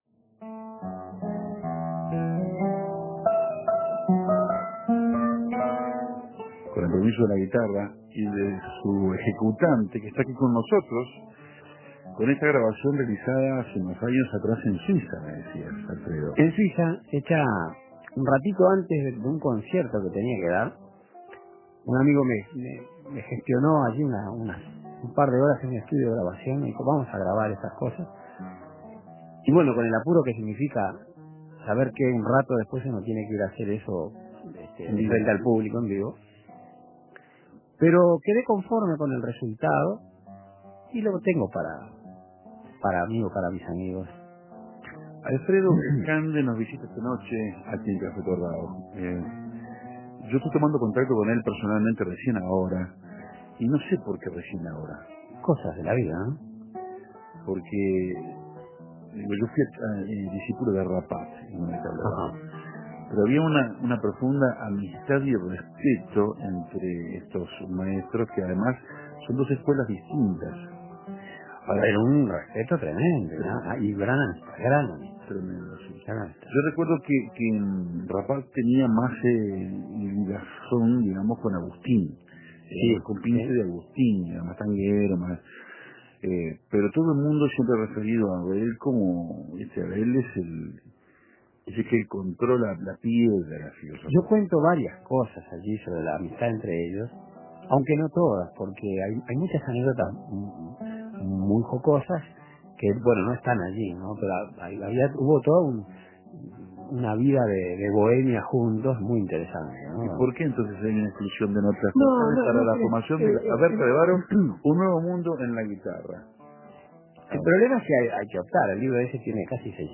Entre bordonas y primas, punteando y rasgando hicimos sonar las historias compartidas con Abel Carlevaro.